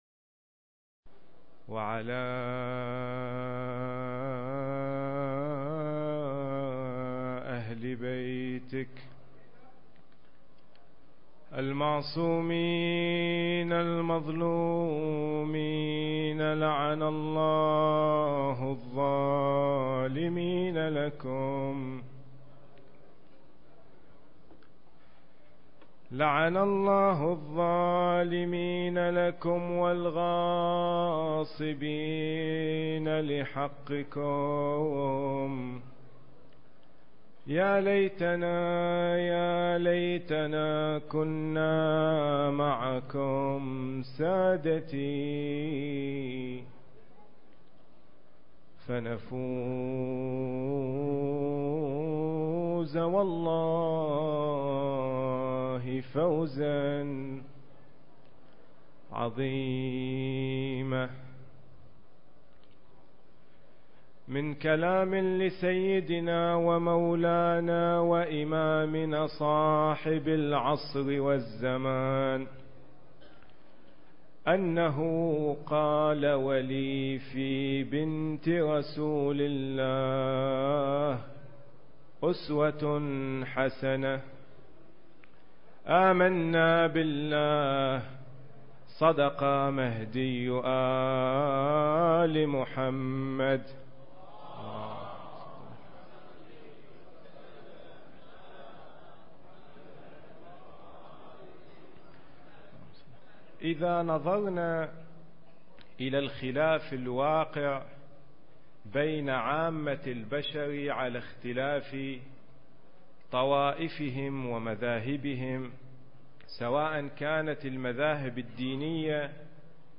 المكان: مضيف الإمام الحسن (عليه السلام) التاريخ: 1434 للهجرة